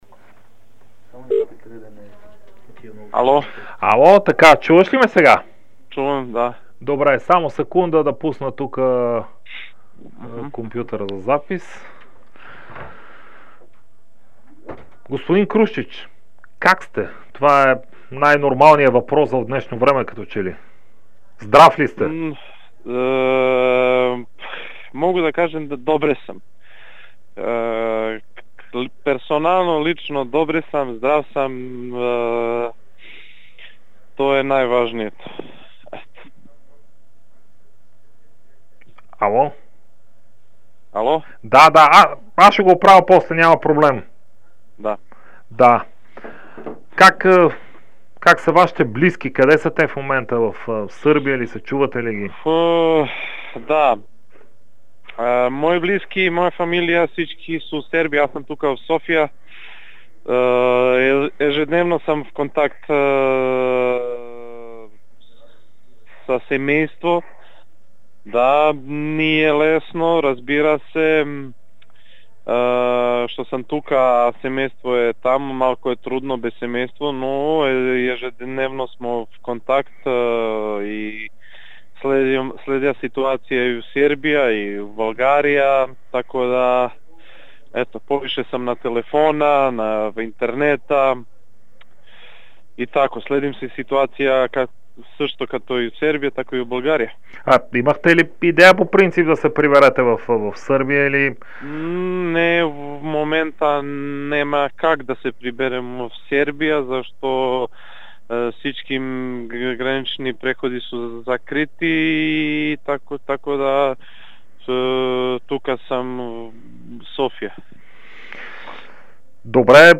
Старши треньорът на ЦСКА Милош Крушчич даде интервю пред Дарик и dsport, в което разкри, че в клуба не са обсъждали темата за намаляването на възнагражденията на футболистите си.